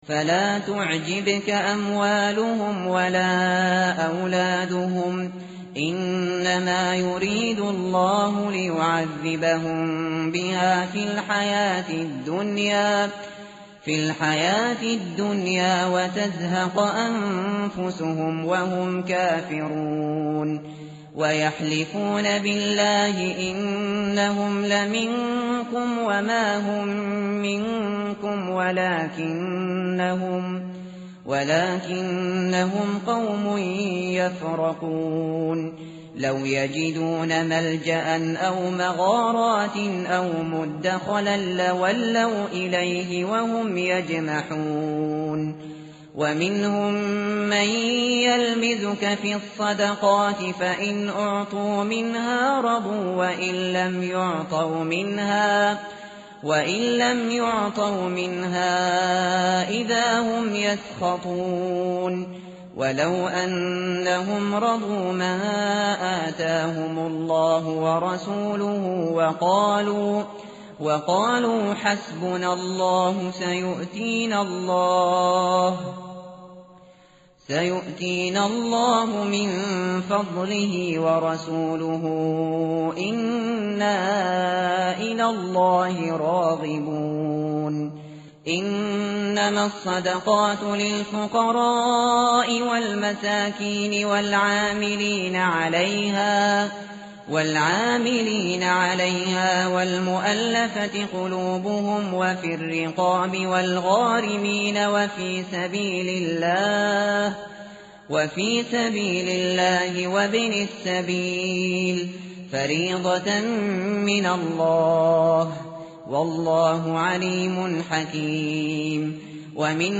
متن قرآن همراه باتلاوت قرآن و ترجمه
tartil_shateri_page_196.mp3